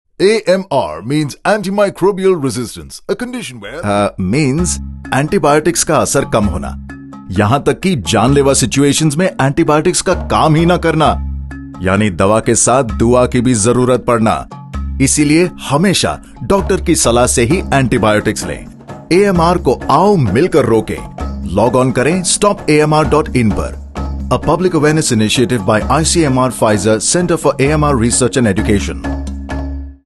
Audio interviews and radio clips, offering health information and glimpses into the diverse facets of ICMR's work.
AIR talk : Awareness of Ebola